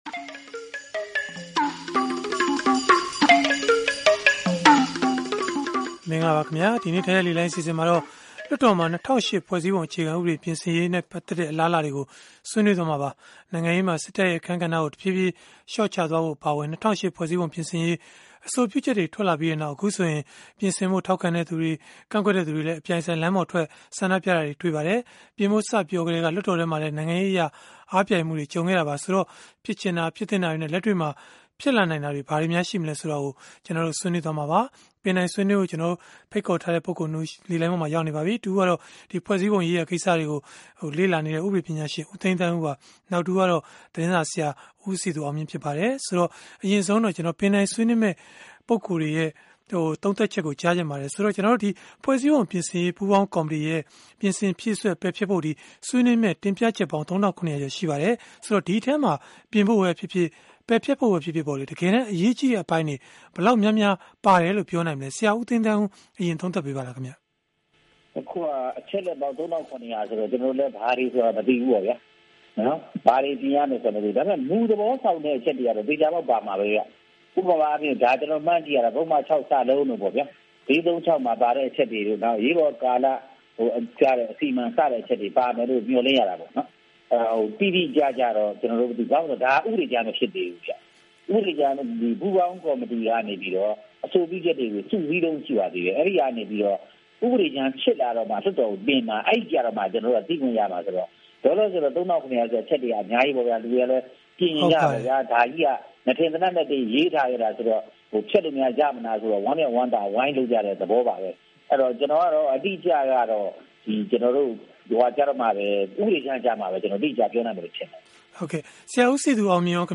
နိုင်ငံရေးမှာ စစ်တပ်ရဲ့ အခန်းကဏ္ဍကို တဖြည်းဖြည်း လျှော့ချသွားဖို့ အပါအဝင် ၂၀၀၈ ဖွဲ့စည်းပုံ အခြေခံဥပဒေ ပြင်ဆင်ရေး လက်တွေ့ ဘယ်လောက်အထိ ဖြစ်လာနိုင်သလဲ ဆိုတာတွေကို ဗွီအိုအေရဲ့ စနေနေ့ည တိုက်ရိုက်လေလှိုင်း အစီအစဉ်မှာ